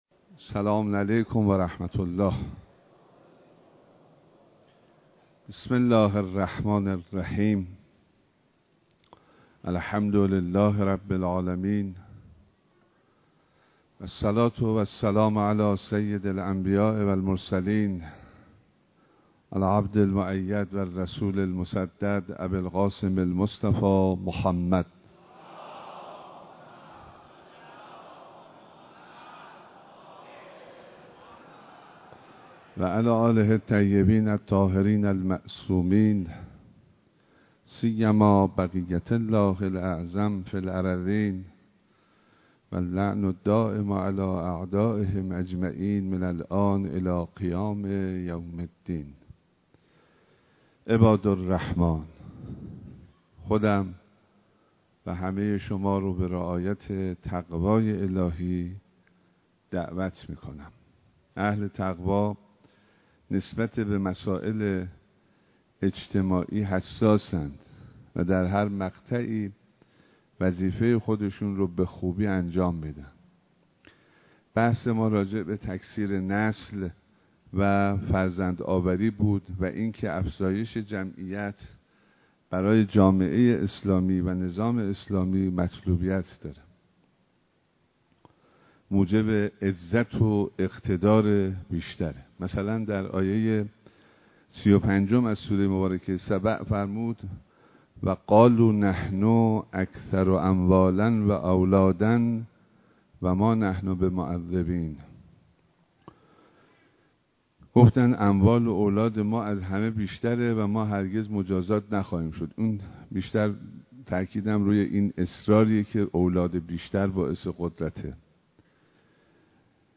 ایراد خطبه‌های نماز جمعه شهرستان کرج به امامت آیت‌الله حسینی همدانی نماینده ولی‌فقیه در استان البرز و امام‌جمعه کرج
صوت خطبه‌های نماز جمعه دوم تیرماه شهرستان کرج
به گزارش روابط عمومی دفتر نماینده ولی‌فقیه در استان البرز و امام‌جمعه کرج، نماز جمعه دوم تیرماه هزار و چهارصد و دو شهرستان کرج به امامت آیت‌الله حسینی همدانی در مصلای بزرگ امام خمینی (ره) برگزار شد.